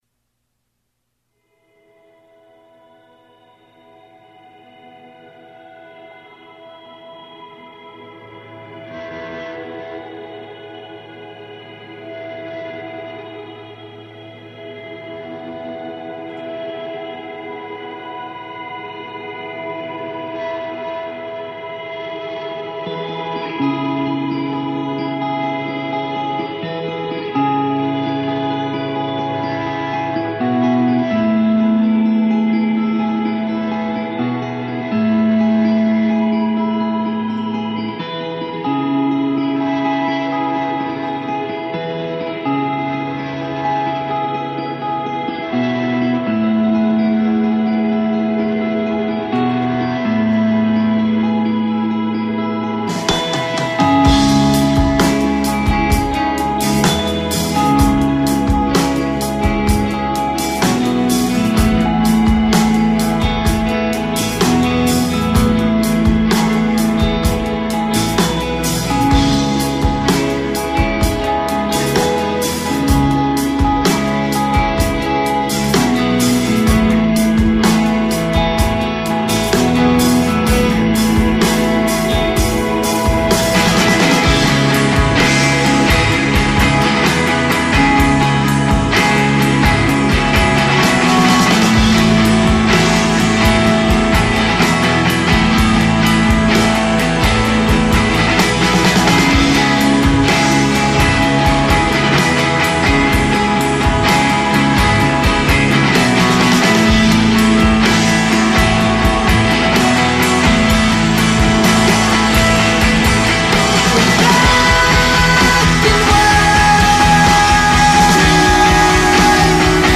rockers